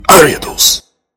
Audio / SE / Cries / ARIADOS.mp3